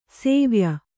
♪ sēvya